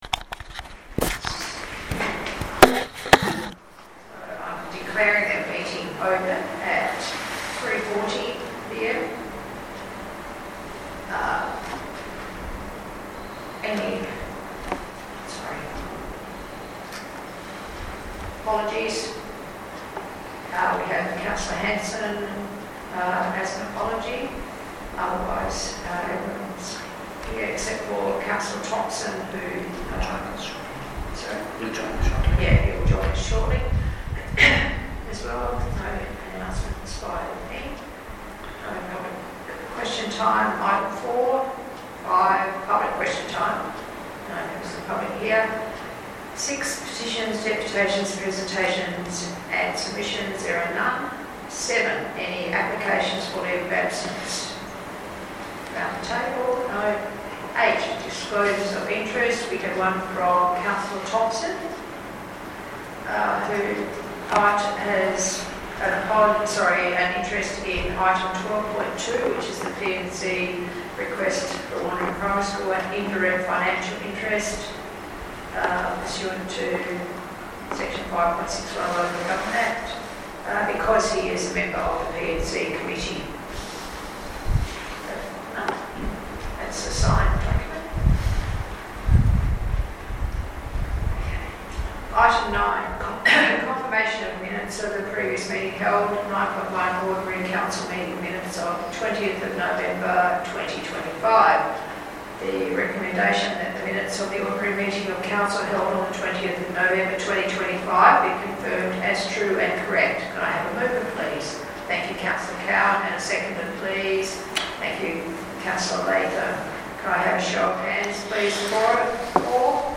december-2025-ordinary-council-meeting-recording.mp3